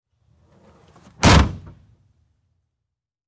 Звуки багажника
Громкий хлопок при захлопывании двери багажника